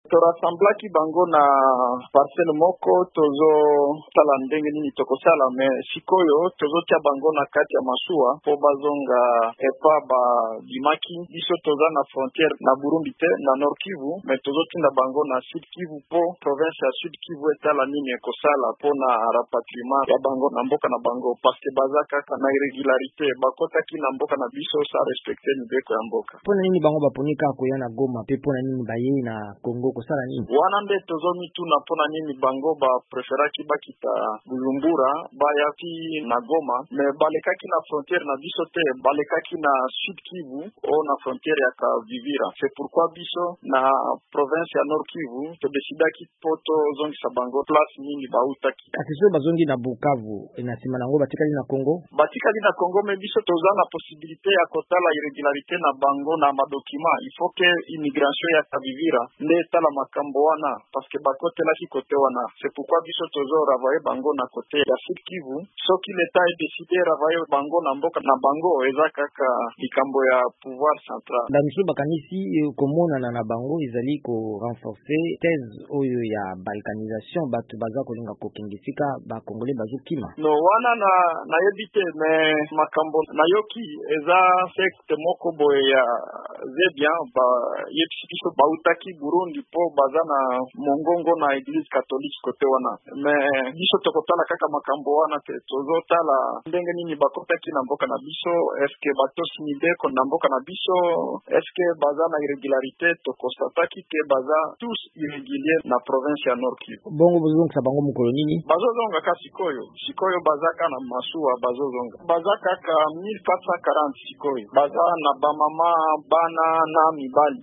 VOA Lingala ebengaki mokambi ya Nord-Kivu, Chalry Kasivita Nzanzu.